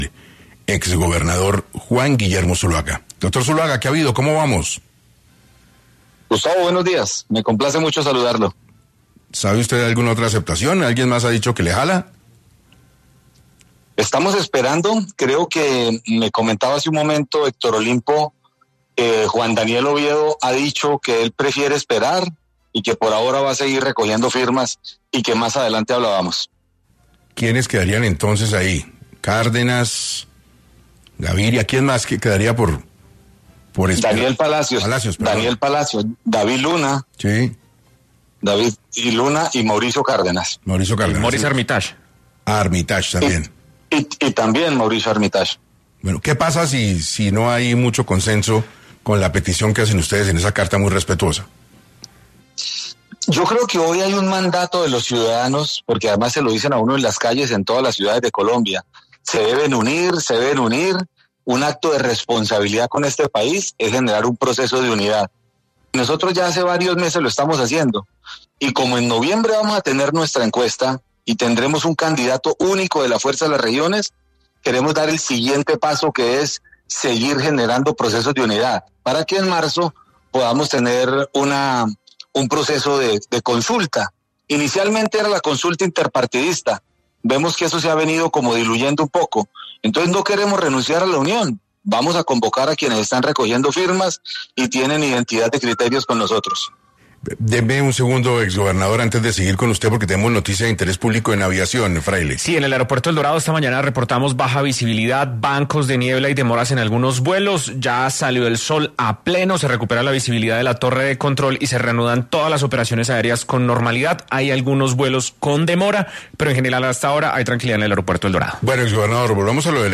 En entrevista con 6AM de Caracol Radio, el exgobernador Zuluaga, mencionó que Oviedo prefiere esperar y seguir recogiendo firmas, mientras que otros nombres como Cárdenas, Palacios Gaviria y Armitage están en consideración.